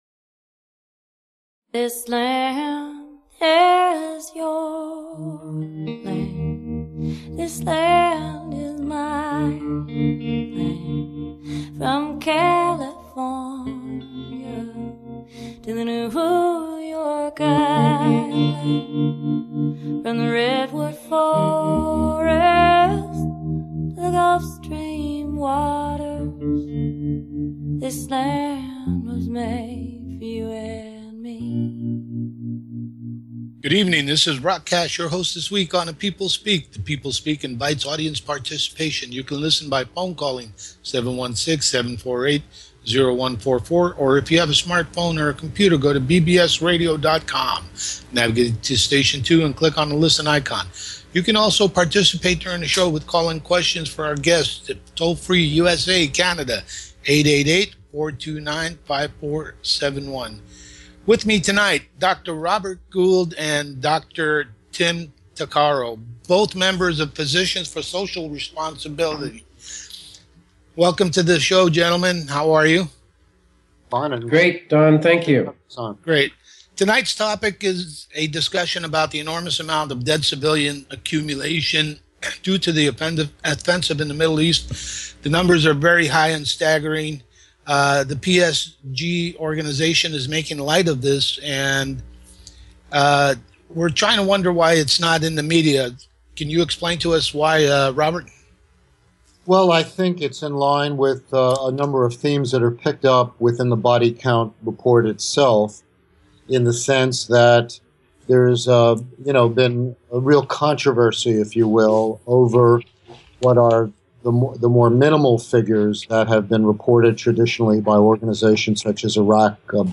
Talk Show Episode, Audio Podcast, The People Speak and Drs.